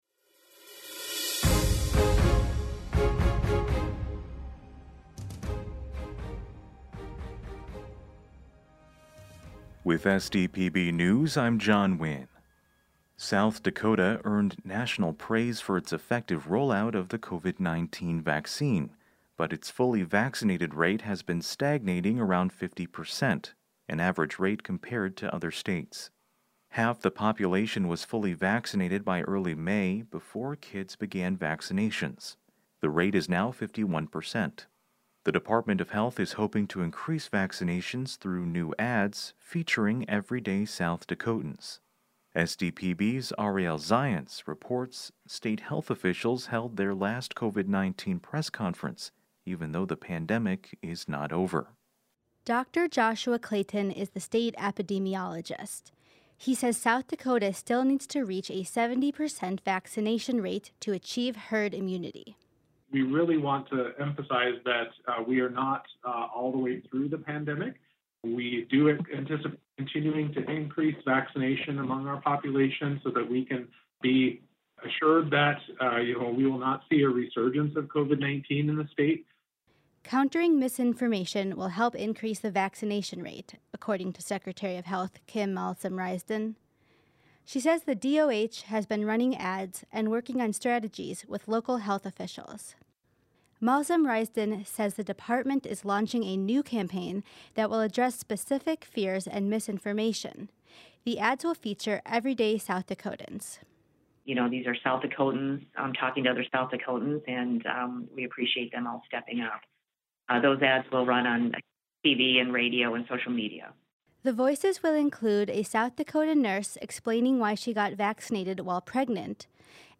We then compile those stories into one neatly formatted daily podcast so that you can stay informed.